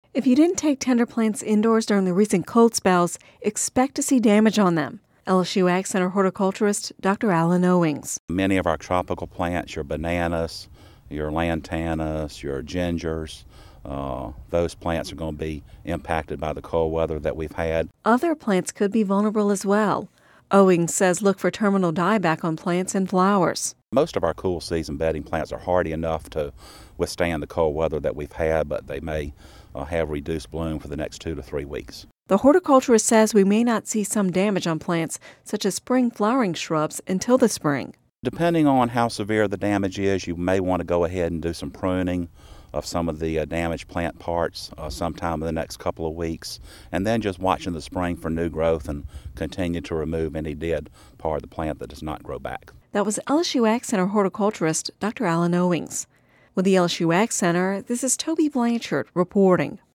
(Radio 01/18/10) If you didn’t take tender plants indoors during the recent cold spell, you can expect to see damage to them.